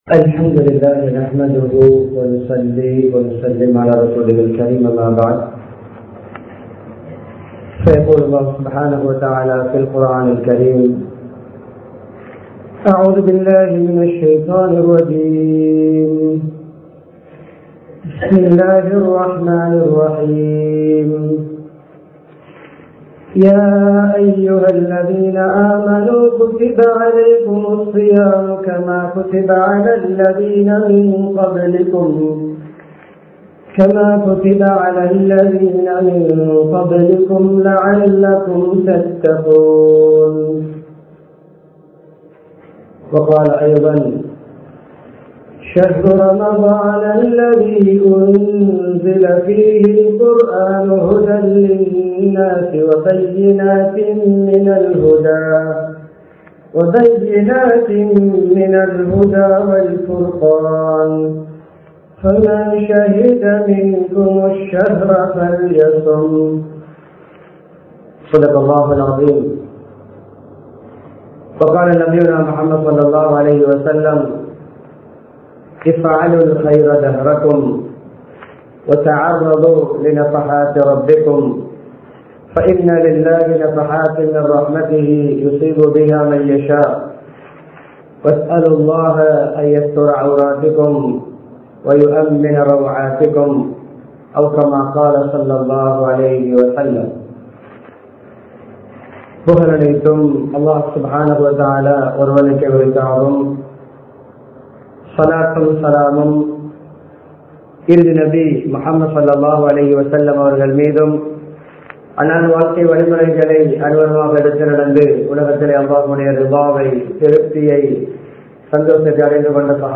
ரமழானை பயனாக கழிப்போம் | Audio Bayans | All Ceylon Muslim Youth Community | Addalaichenai
Colombo 09, Minnan Jumua Masjith